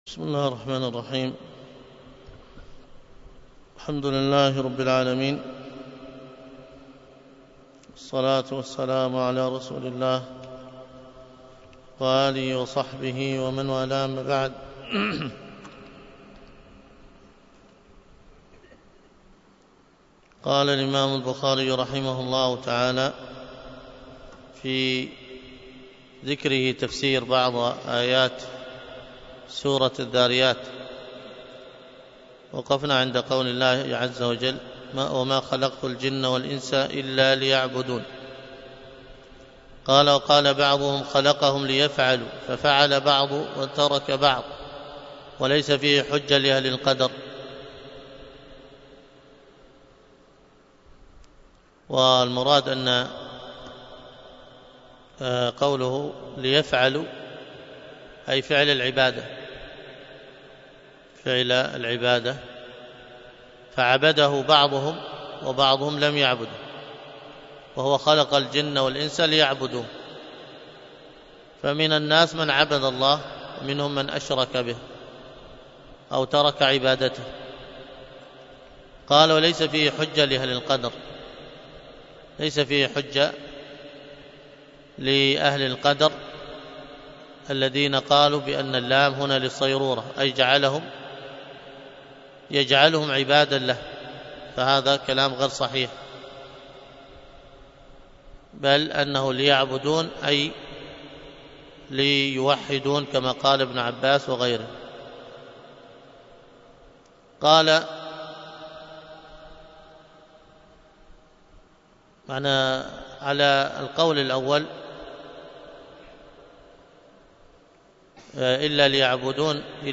الدرس في كتاب التفسير من صحيح البخاري 188،